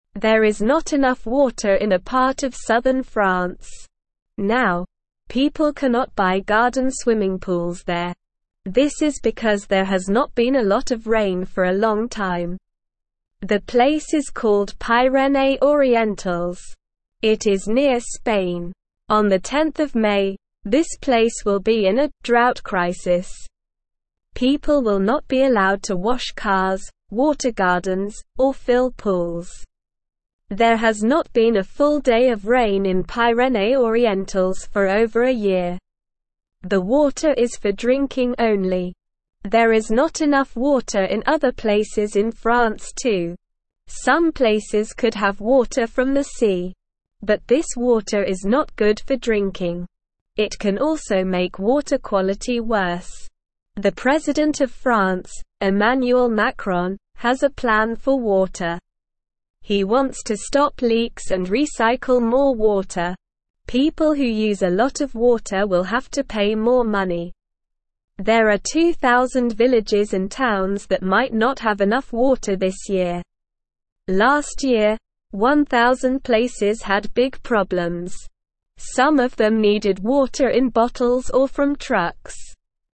Slow
English-Newsroom-Beginner-SLOW-Reading-No-Swimming-Pools-in-Dry-French-Area.mp3